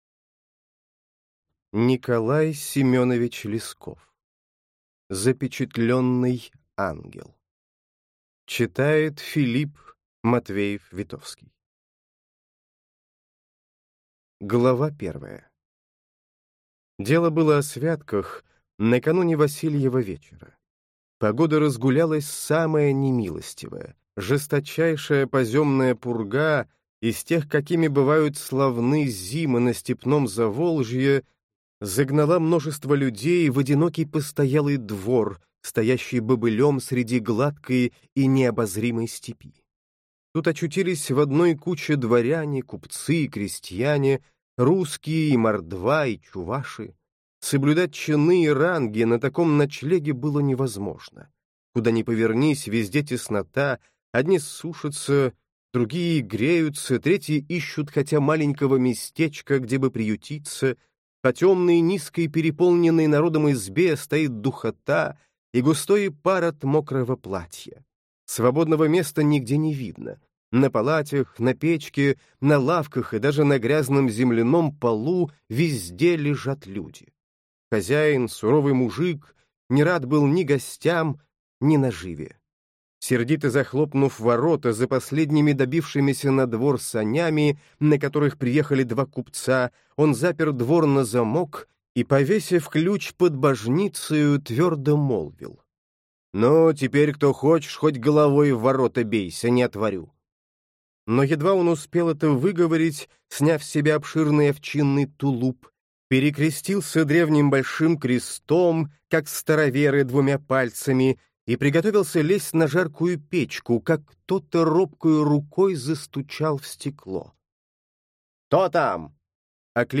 Аудиокнига Запечатленный ангел. Сборник | Библиотека аудиокниг